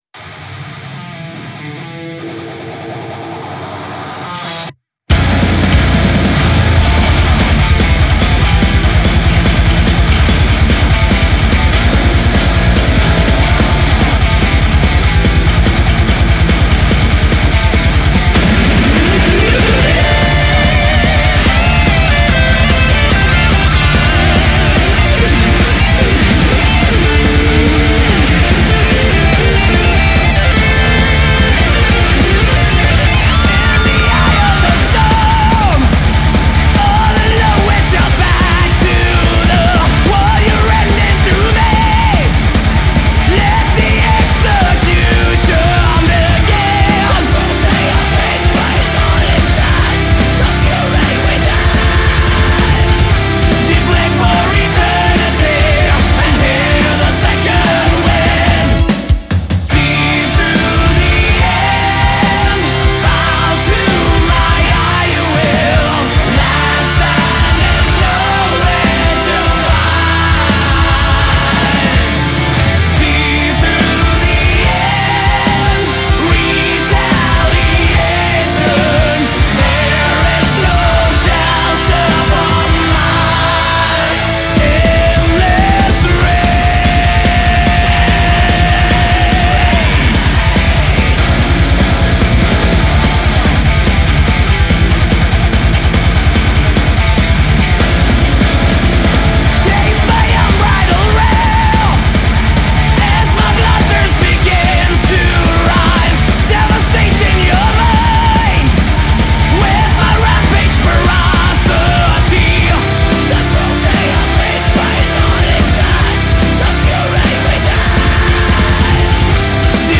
Жанр: Power Metal